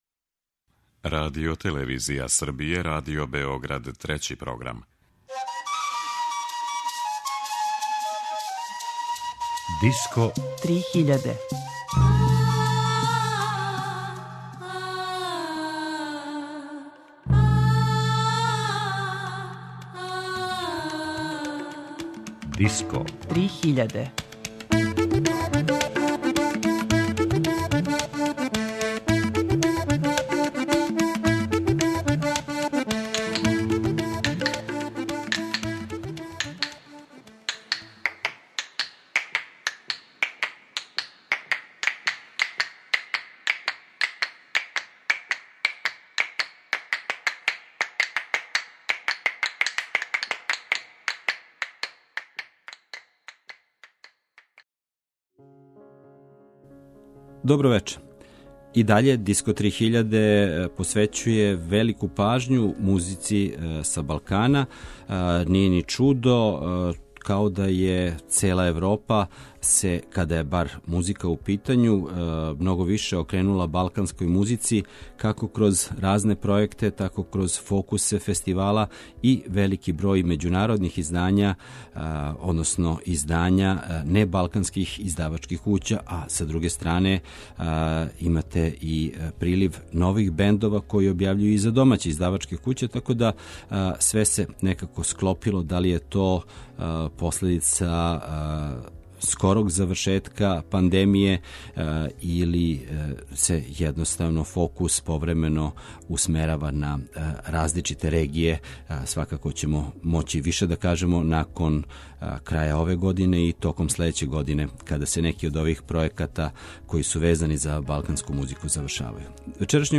У вечерашњој емисији још једном ћемо потврдити да музика Балкана ове године доминира кад је у питању етно звук.